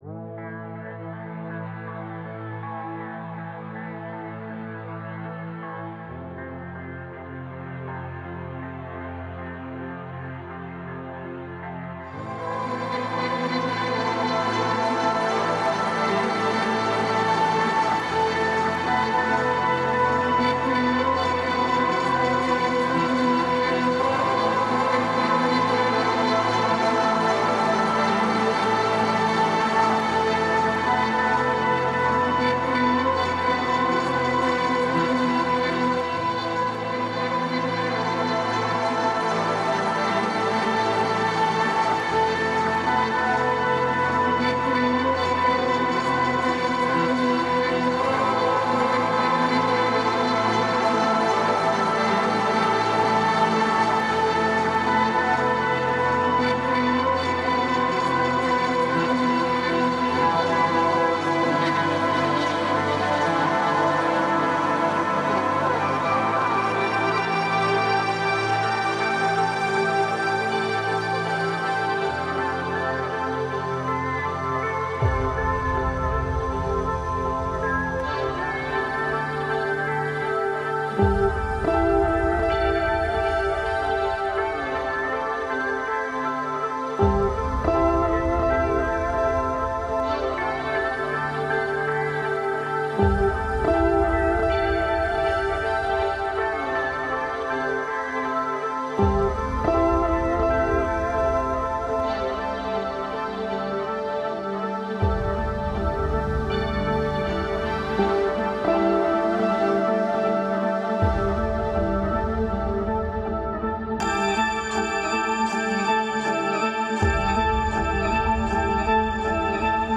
Ambient Trance Prog